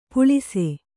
♪ puḷise